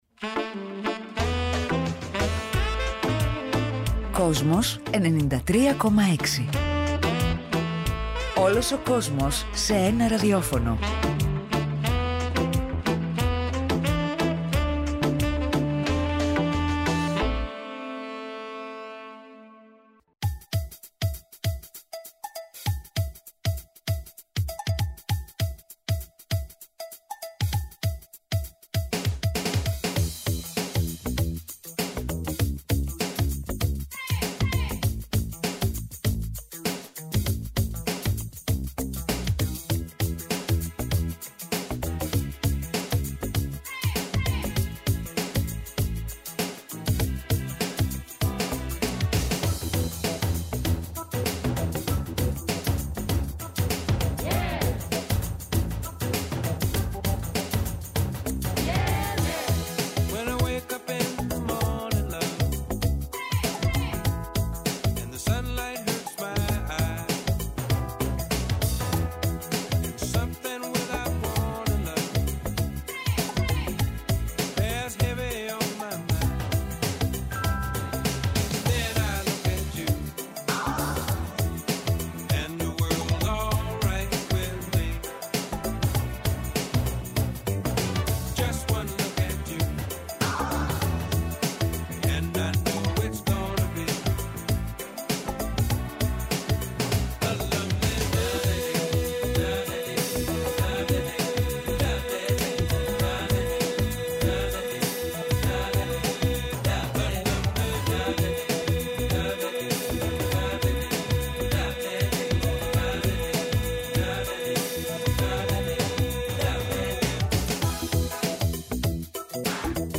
Ο αρχιτέκτονας του “πολιτικού funk” των 70s, Brian Jackson, στο στούντιο του Kosmos 93,6 | 30.10.2025